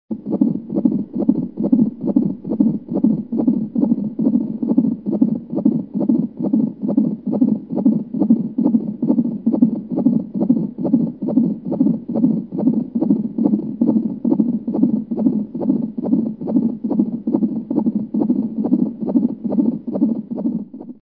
دانلود آهنگ قلب انسان با ریتم تند از افکت صوتی انسان و موجودات زنده
جلوه های صوتی
دانلود صدای قلب انسان با ریتم تند از ساعد نیوز با لینک مستقیم و کیفیت بالا